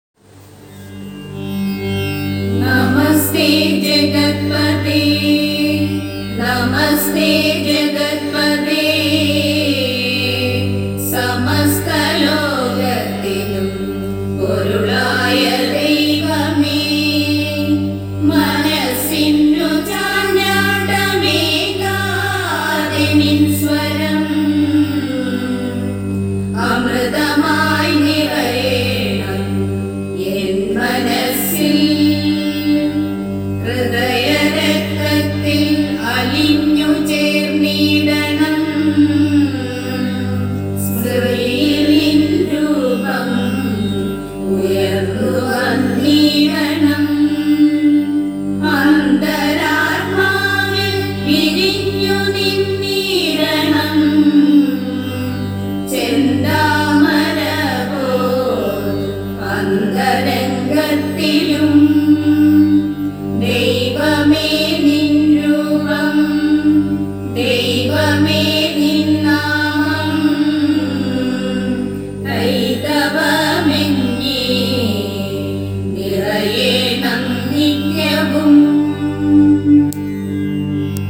prayer-song-kpss.mp3